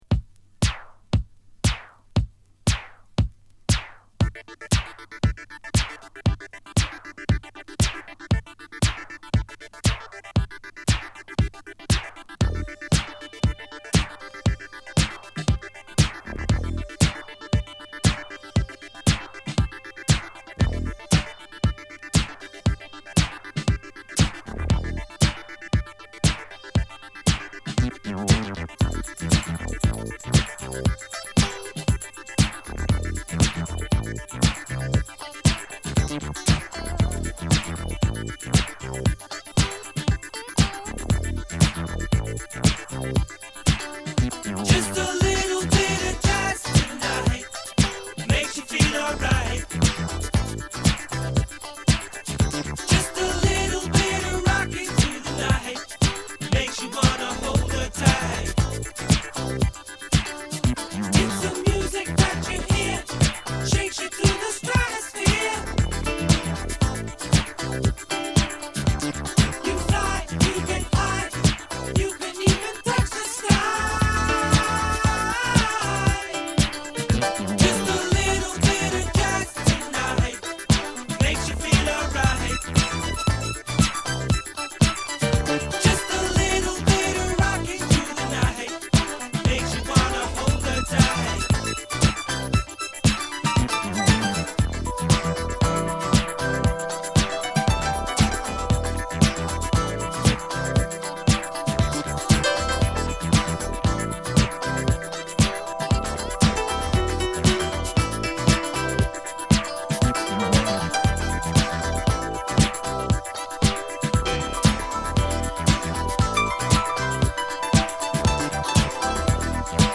軽快なボトムラインになぞるコーラスワークが素敵なディスコ・ブギー名曲！！中盤にピアノプレイが炸裂！